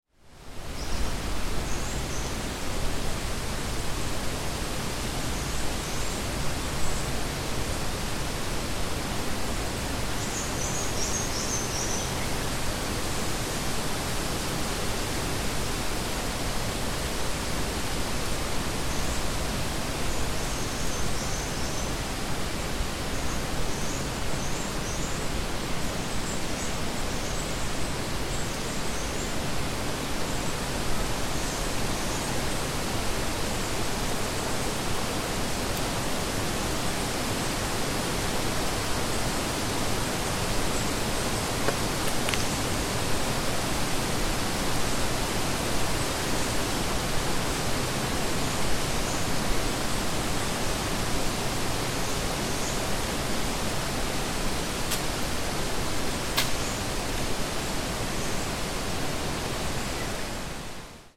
Осенняя атмосфера лесного простора